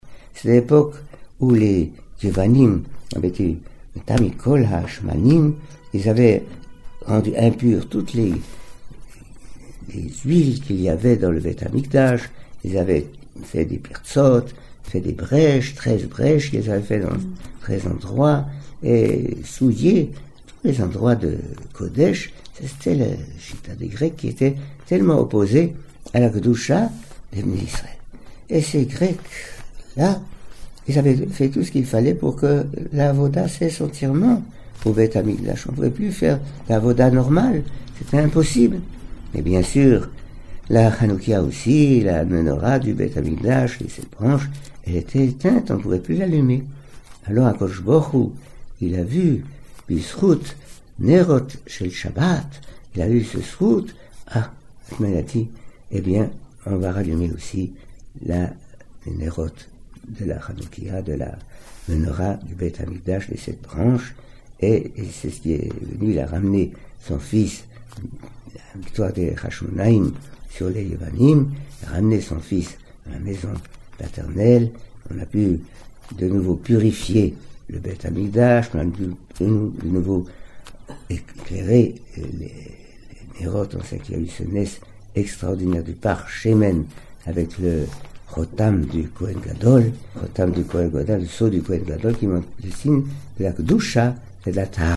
C’est ainsi que Dvar Torah a eu le grand privilège d’enregistrer l’une de ses rencontres et le cours qu’il y dispensa 8 jours avant ‘Hanouka à Epinay.